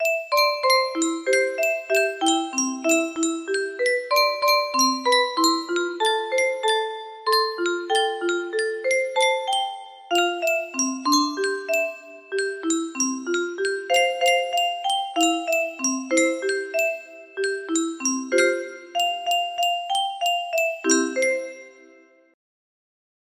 K15 version because there's not enough of them